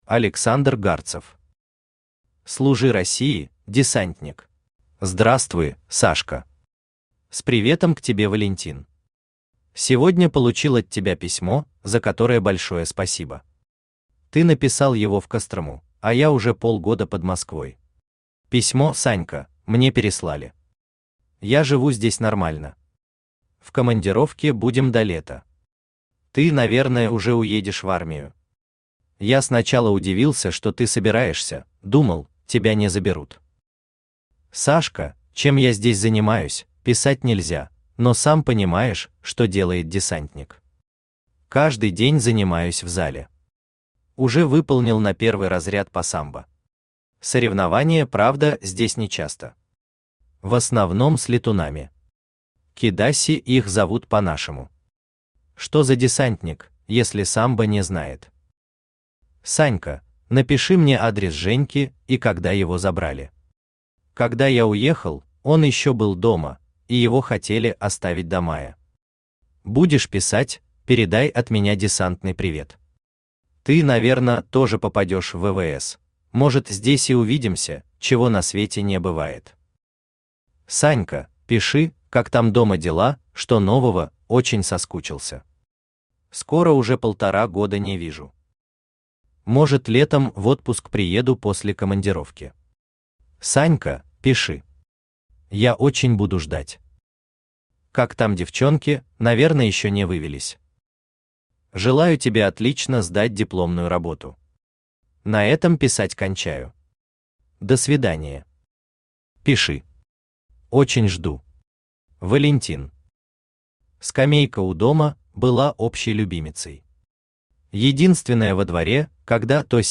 Аудиокнига Служи России, десантник | Библиотека аудиокниг
Читает аудиокнигу Авточтец ЛитРес.